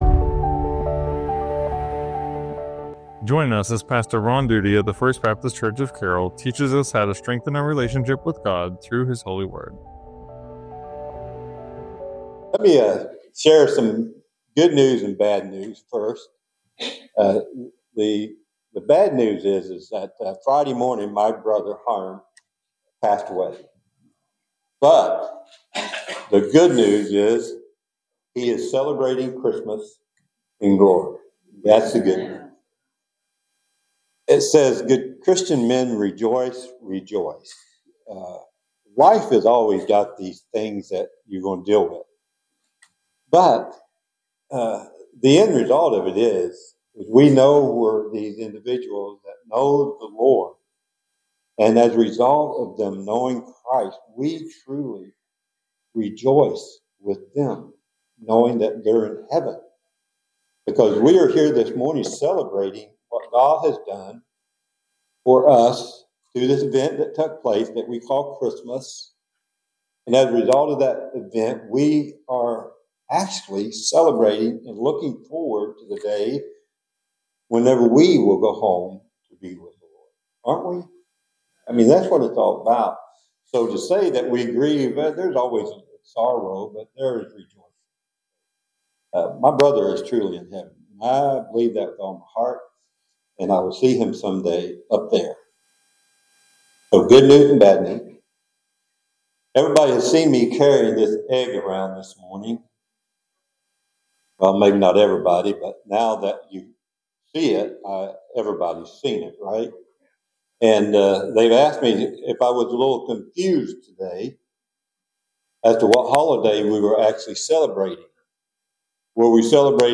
Sermons | First Baptist Church of Carroll